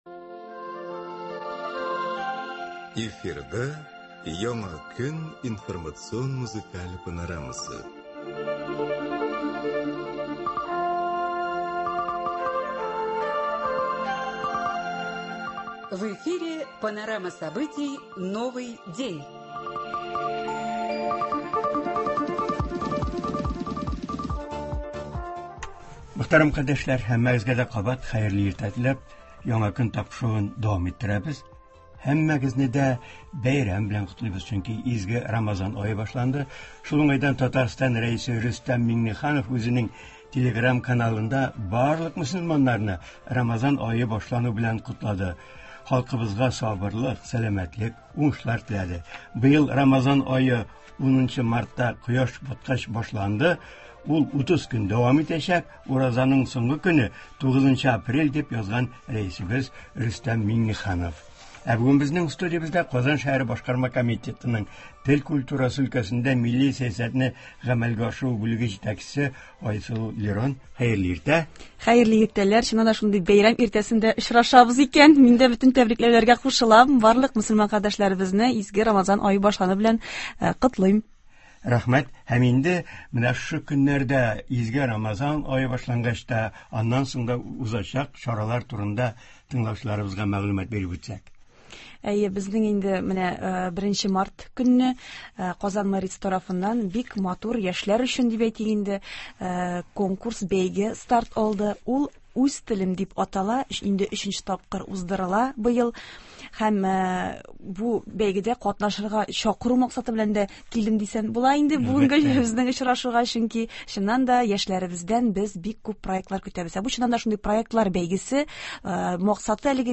тыңлаучылардан килгән сорауларга җавап бирә.